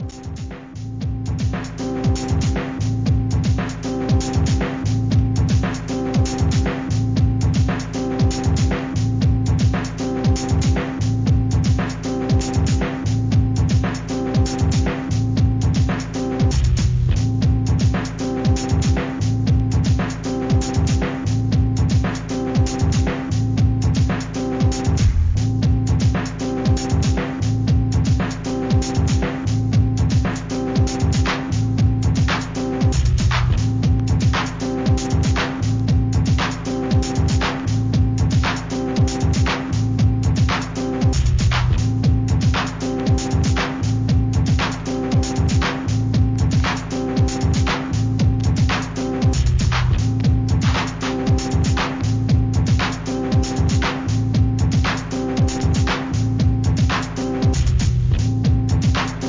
HIP HOP/R&B
ブレイク・ビーツ!!